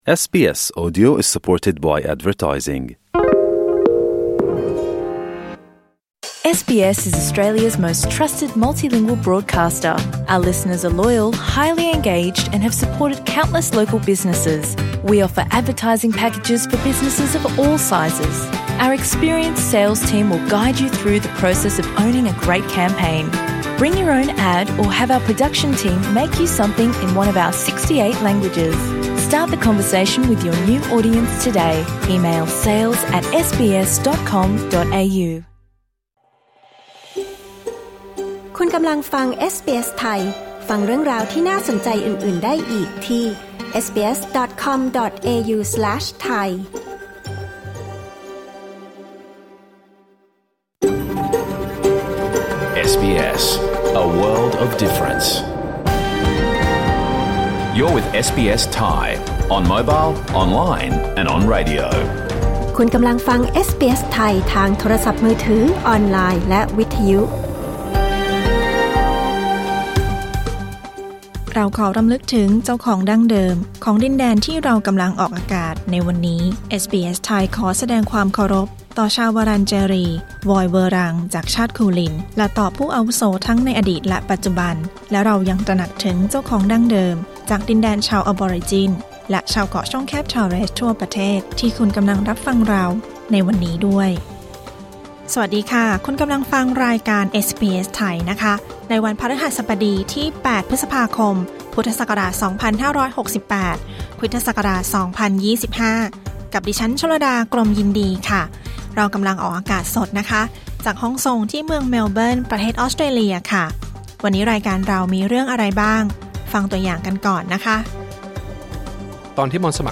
รายการสด 8 พฤษภาคม 2568